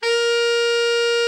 Index of /90_sSampleCDs/Giga Samples Collection/Sax/TENOR VEL-OB